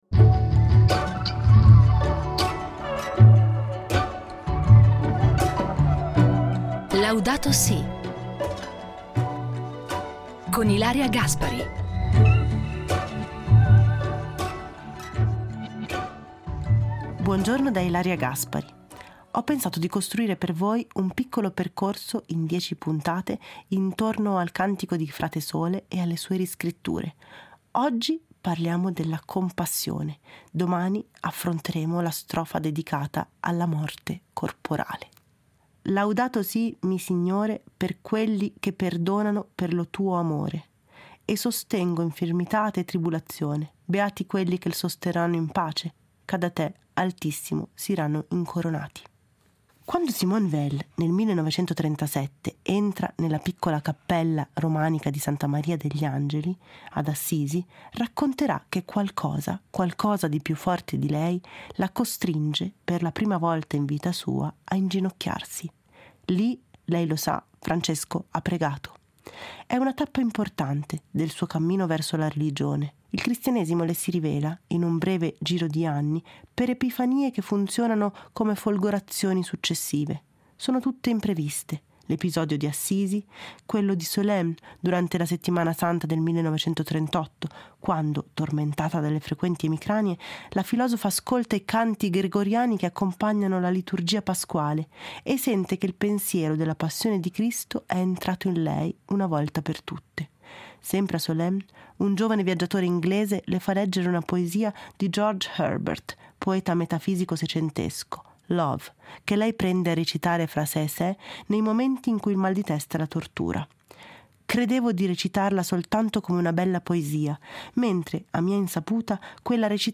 Il Cantico dei cantici letto da Ilaria Gaspari